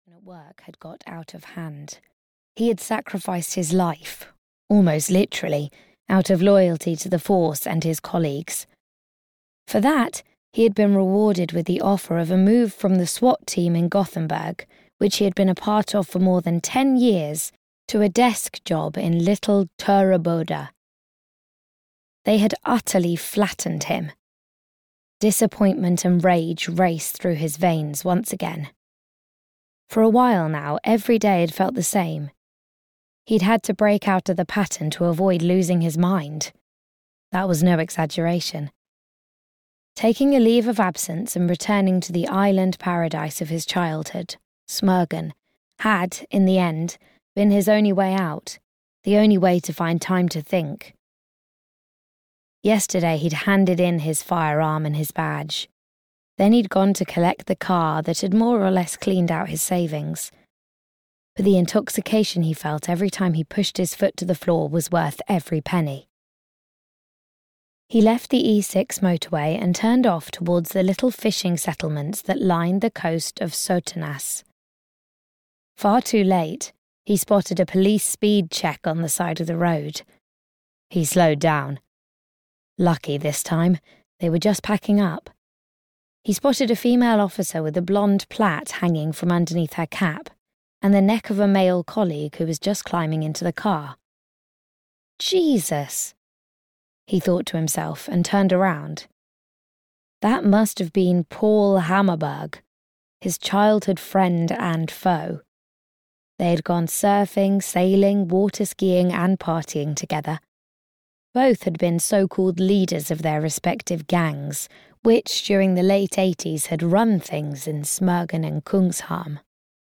The Man on the Beach (EN) audiokniha
Ukázka z knihy